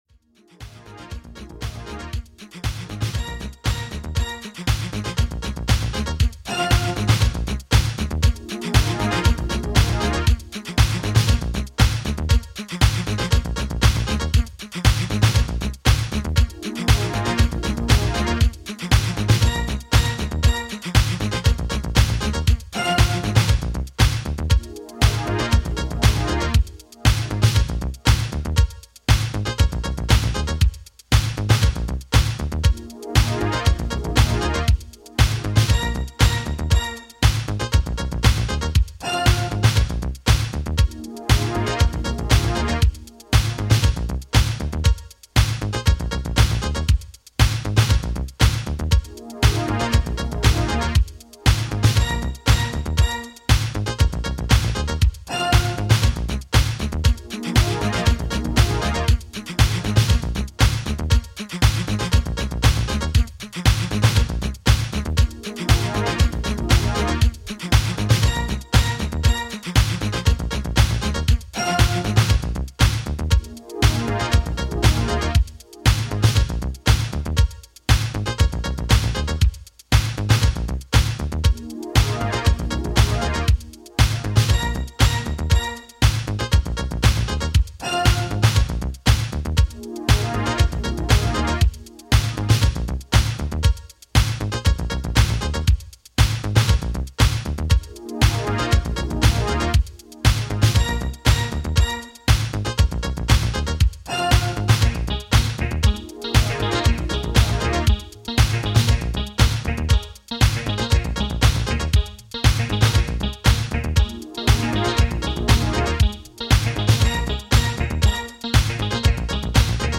[90SHOUSE]